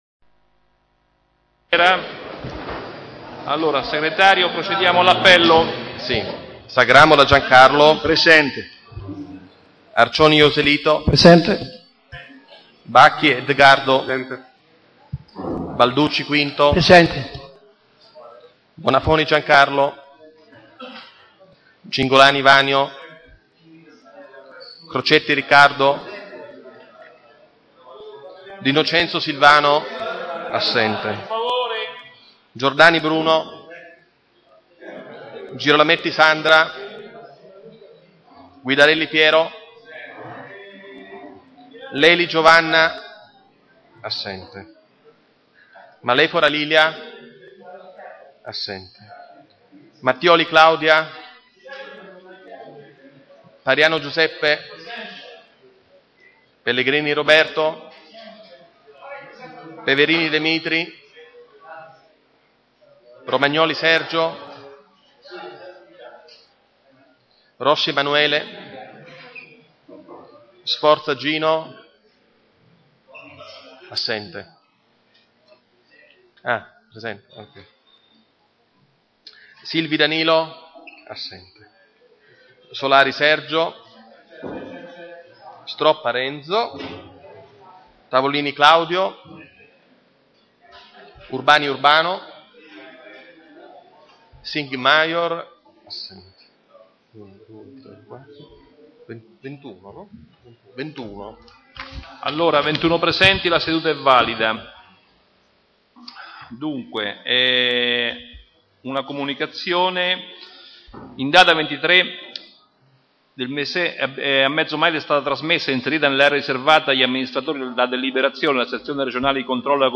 Ai sensi dell'art. 20, comma 7, dello Statuto Comunale e dell'articolo 14 del regolamento consiliare, il Consiglio Comunale è convocato presso Palazzo Chiavelli - sala consiliare martedì 28 giugno 2016 alle ore 18